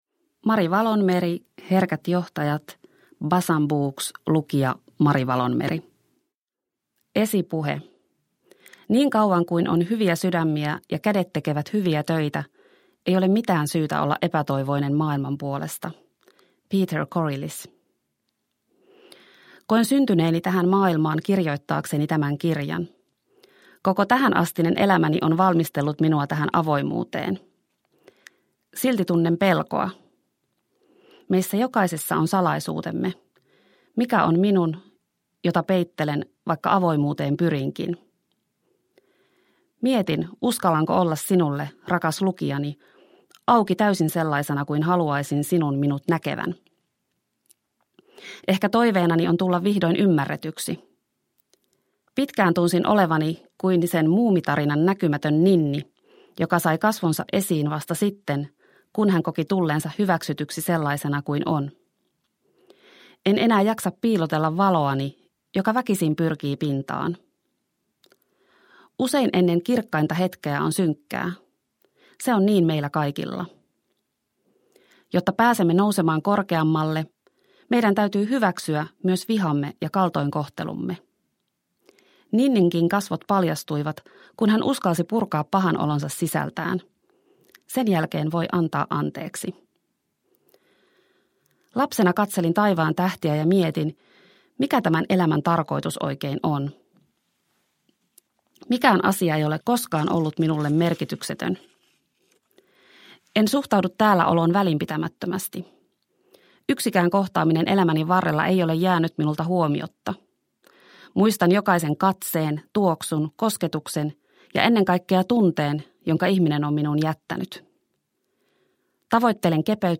Herkät johtajat – Ljudbok